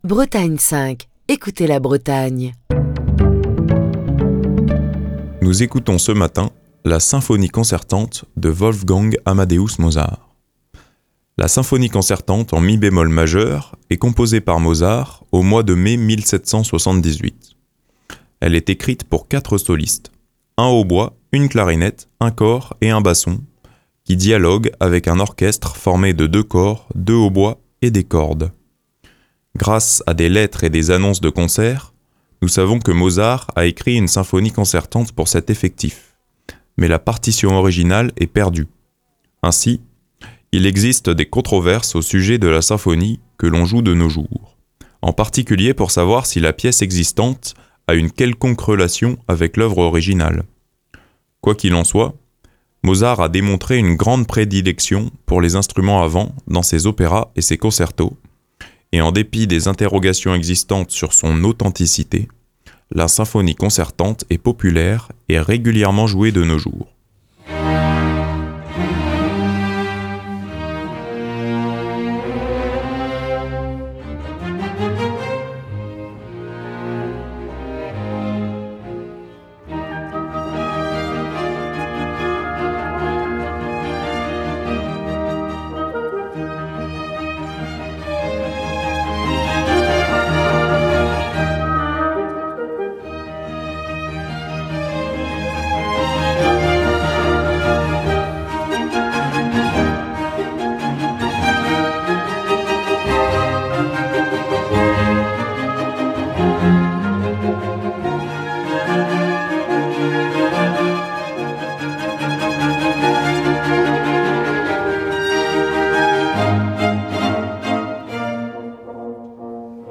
La Symphonie concertante en mi bémol majeur a été composée par Wolfgang Amadeus Mozart au mois de mai 1778. Elle est écrite pour quatre solistes, un hautbois, une clarinette,un cor et un basson, qui dialoguent avec un orchestre formé de deux cors, deux hautbois et des cordes.